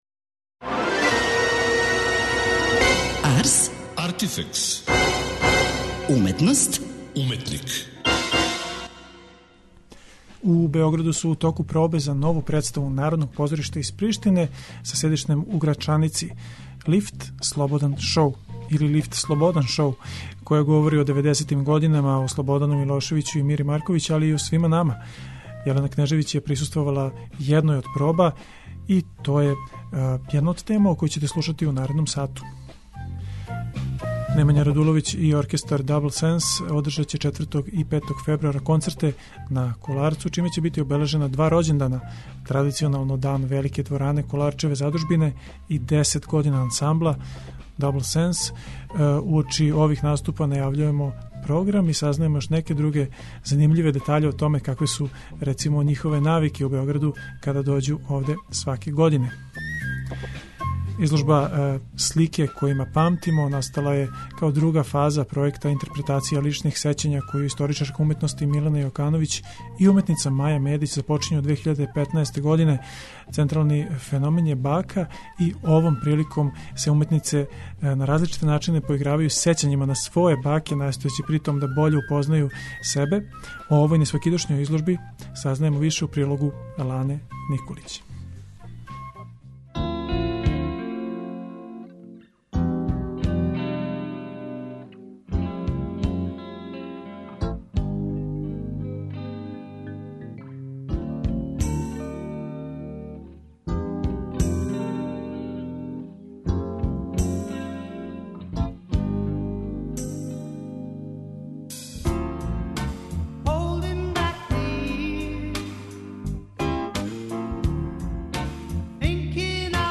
Немања Радуловић уочи концерта на Kоларцу, репортажа са пробе представе „Лифт: Слободан шоу” и изложба о личним сећањима.